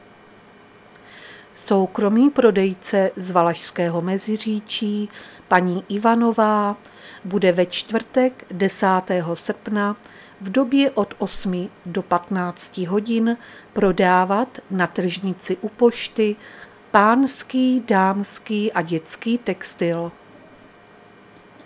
Záznam hlášení místního rozhlasu 9.8.2023